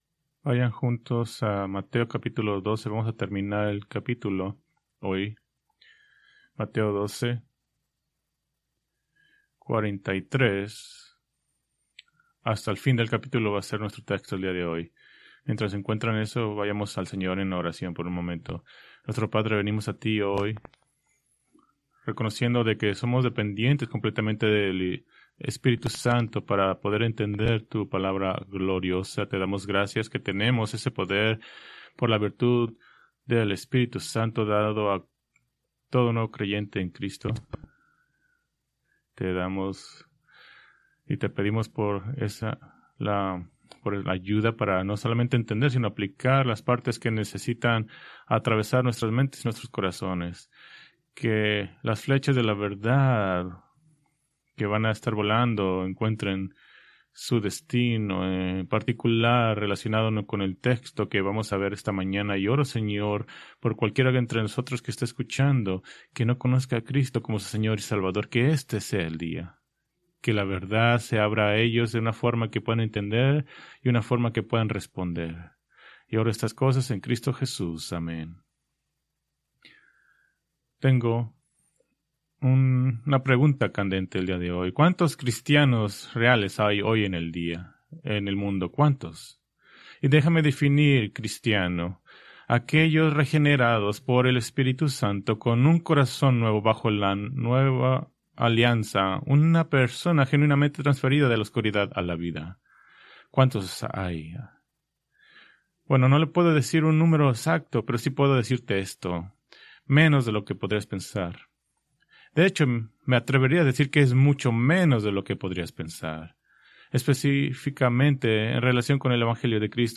Preached January 25, 2026 from Mateo 12:43-50